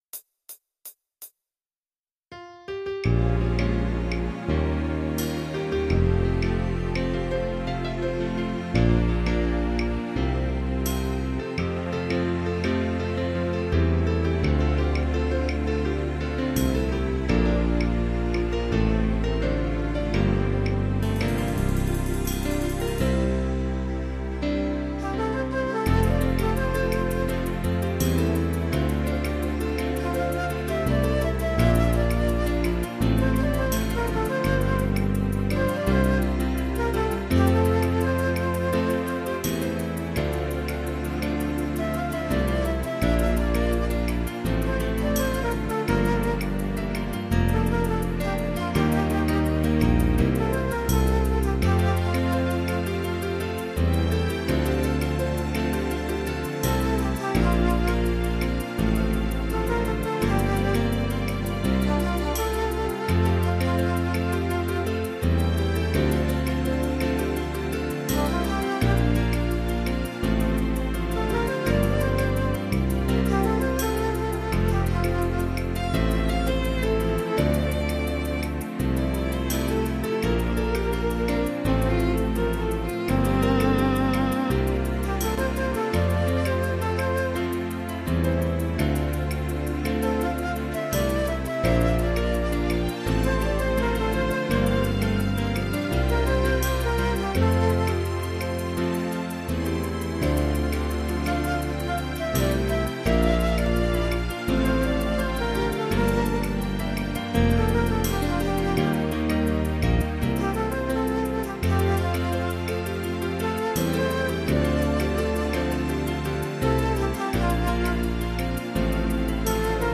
PRO MIDI INSTRUMENTAL VERSION